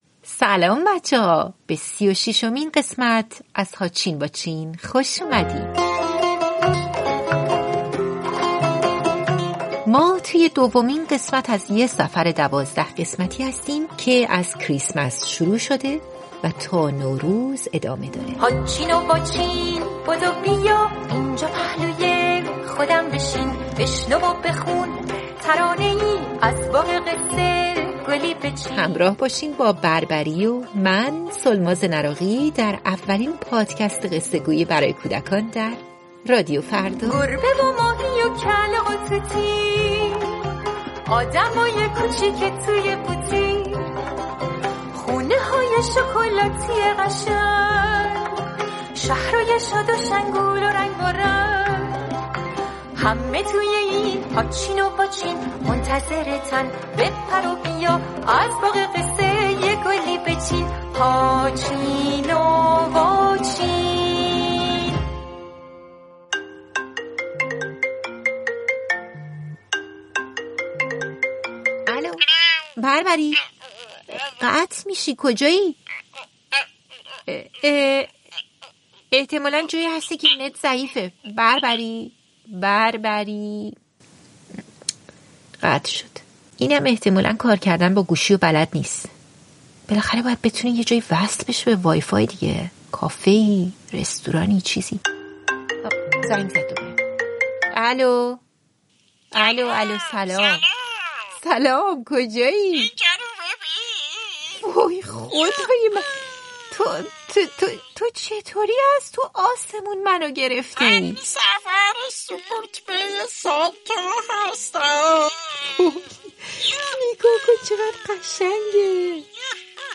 پادکست «هاچین واچین» اولین کتاب صوتی رادیو فردا، مجموعه قصه‌های کودکان است.
بربری توی این قسمت میره به ایتالیا و با «بفانا» که یه جورایی نقش سانتا رو در فرهنگ ایتالیایی داره آشنا میشه. ما با هم تماس تصویری گرفتیم و شما می‌تونین گفتگوی ما رو اینجا بشنوین.